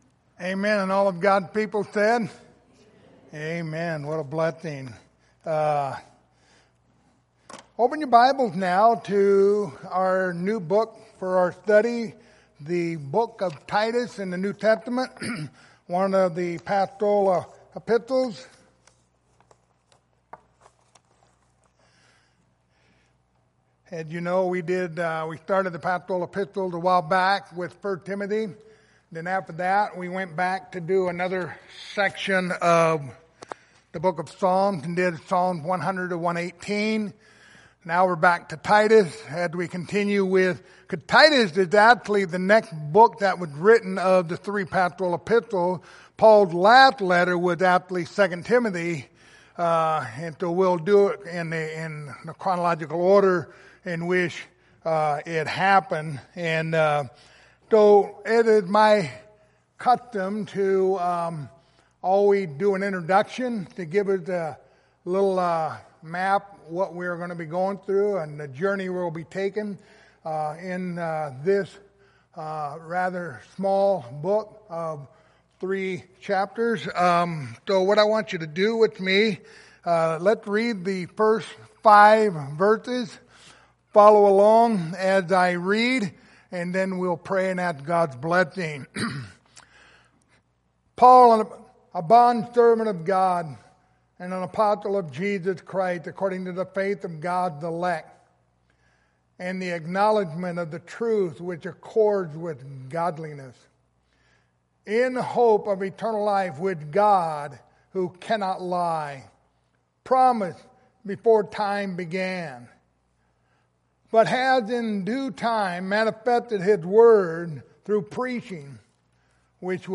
Passage: Titus 1:1-4 Service Type: Sunday Morning